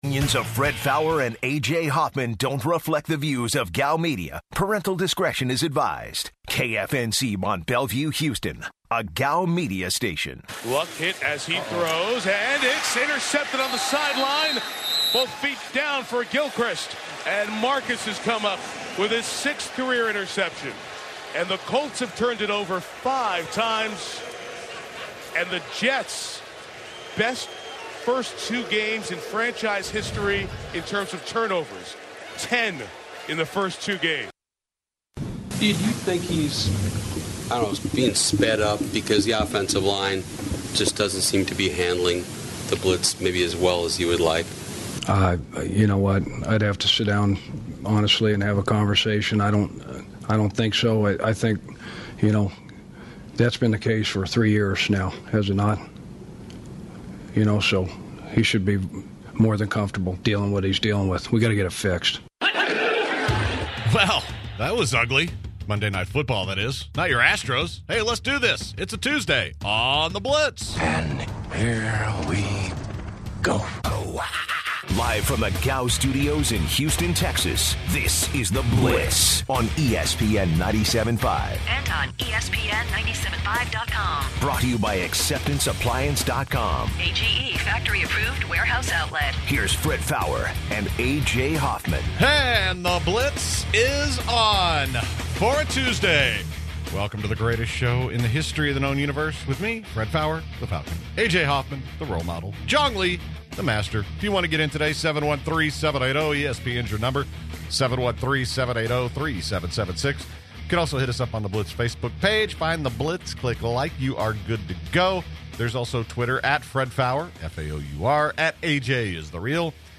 The guys share their thoughts on Monday Night Football and Andrew Luck.